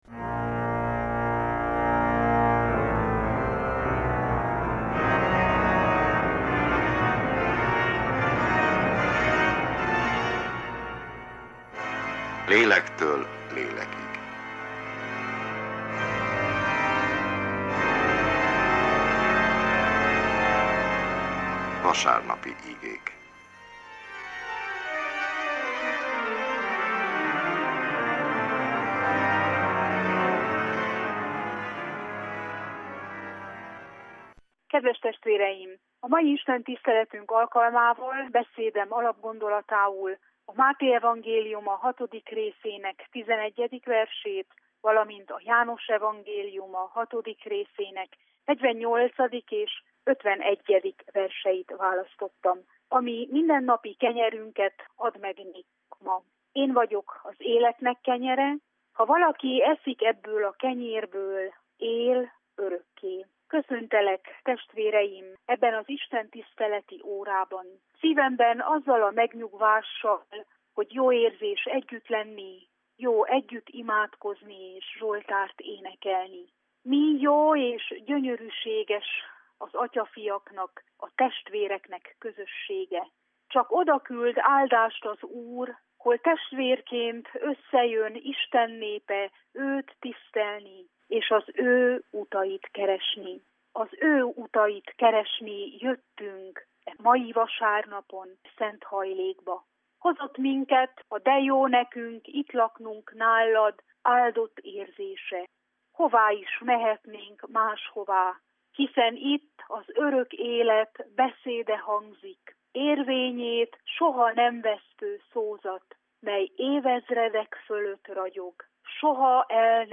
Unitárius igehirdetés, november 22.